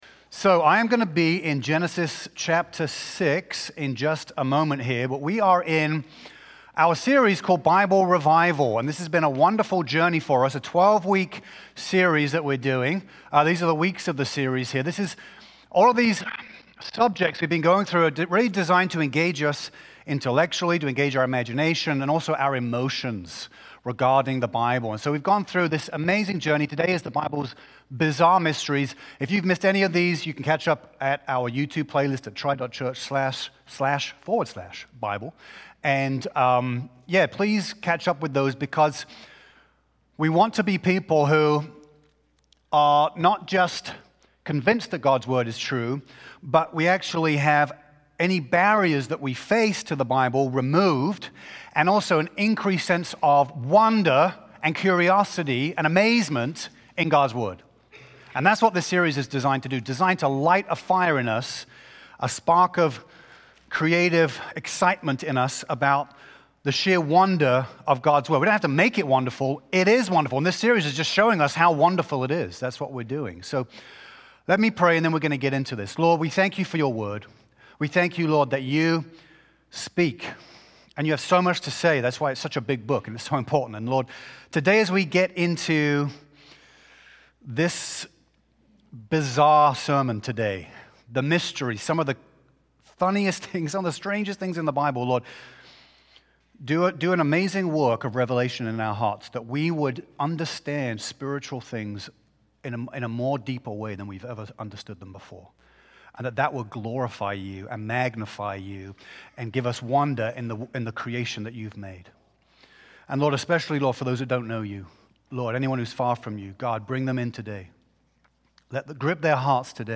NOV-9-Full-Sermon.mp3